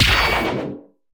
knockeddown_2.ogg